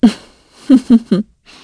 Isolet-Vox-Laugh1_kr.wav